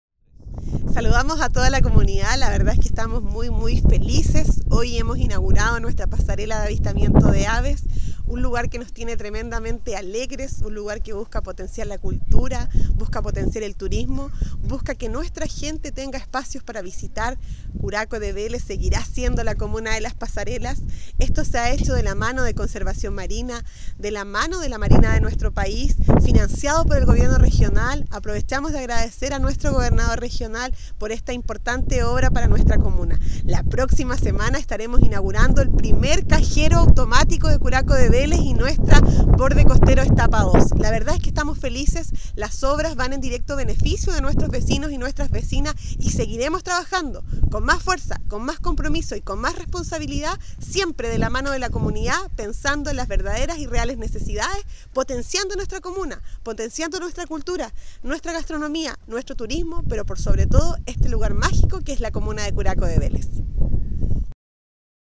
La alcaldesa Javiera Yáñez se refirió a la concreción de esta iniciativa que se ubica en el Santuario de la Naturaleza que tiene en su bahía Curaco de Vélez y que la convierte en un nuevo atractivo comunal:
alcaldesa-curaco-pasarela-.mp3